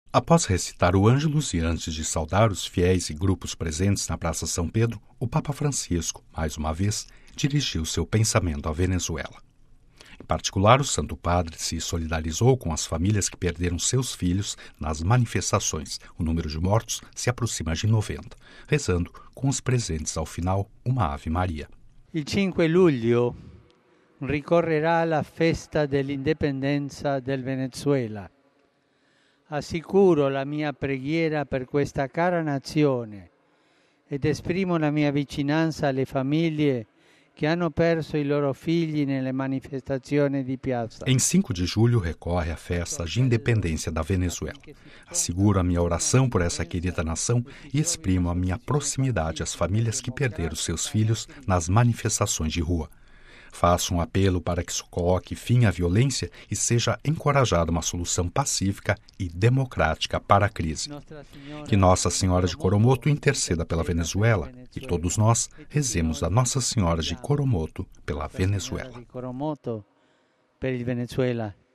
Cidade do Vaticano (RV) – Após recitar o Angelus e antes de saudar os fiéis e grupos presentes na Praça São Pedro, o Papa Francisco, mais uma vez, dirigiu o seu pensamento à Venezuela, que passa por gravde crise social, polítca e econômica.
Em particular, o Santo Padre pediu o fim da violência, solidarizando-se com as famílias que perderam seu filhos nas manifestações – o número de mortos se aproxima de 90 – rezando com os presentes ao final, uma Ave Maria: